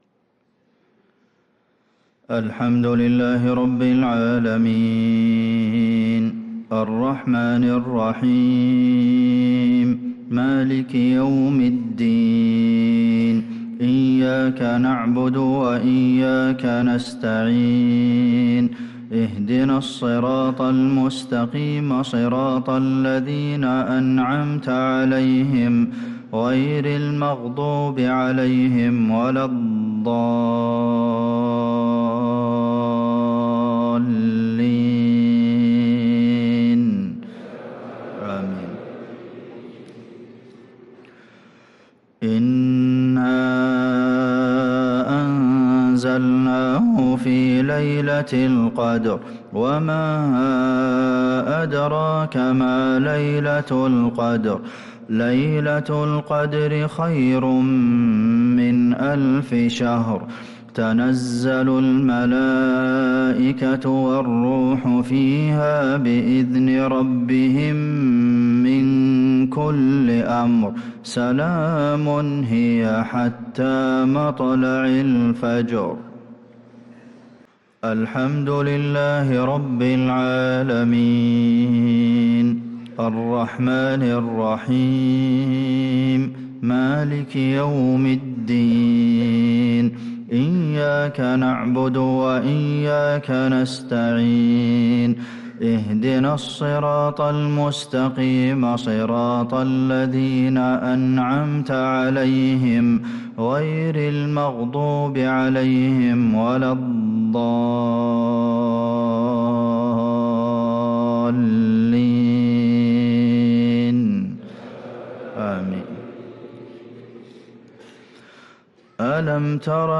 صلاة المغرب ٧-٨-١٤٤٦هـ | سورة القدر و الفيل كاملة | Maghrib prayer from Surah al-qadr & al-Fil | 6-2-2025 > 1446 🕌 > الفروض - تلاوات الحرمين